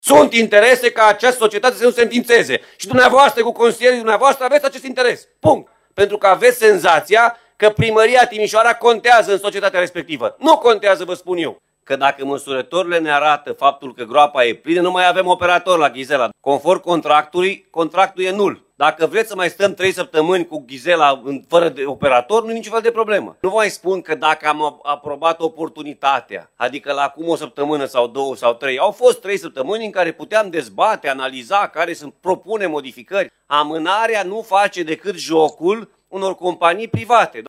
Discuții aprinse în Consiliul Județean Timiș pe tema înființării societății care ar urma să administreze depozitul de deșeuri de la Ghizela.